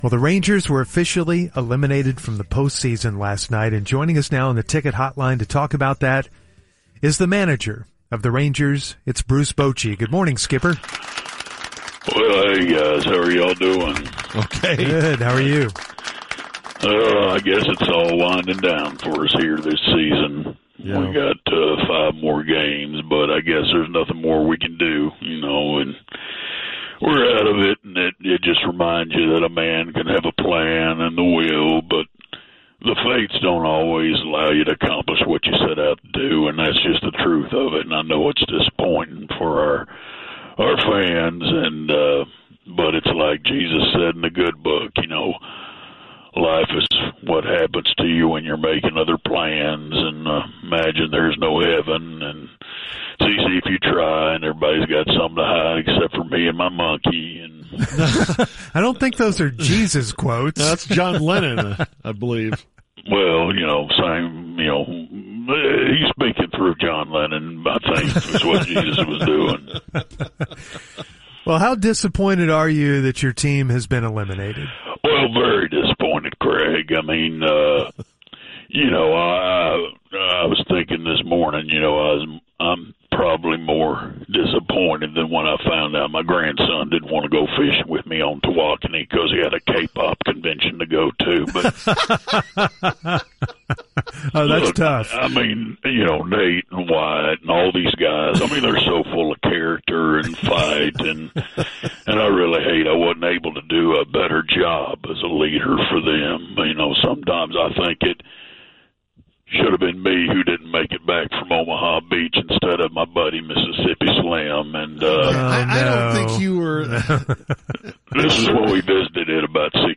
Fake Bruce Bochy – The Musers 9.24.2025